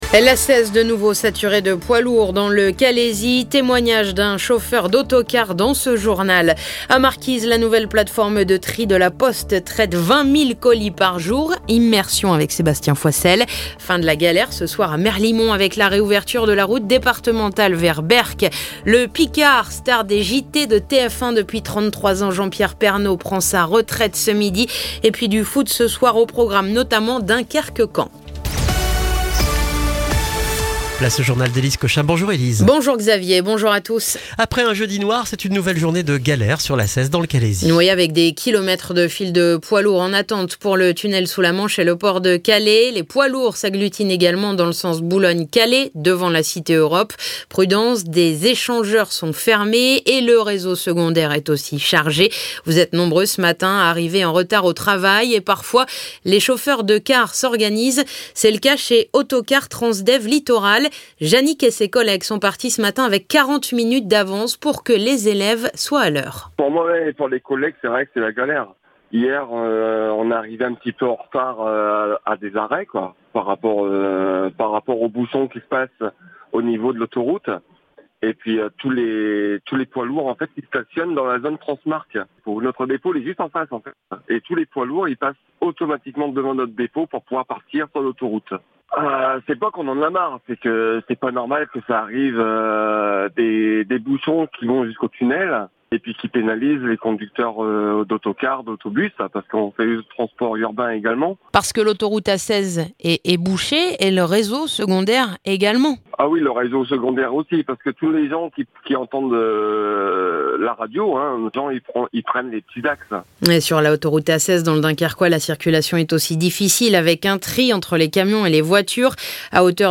Le journal du vendredi 18 décembre